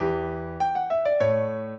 piano
minuet10-8.wav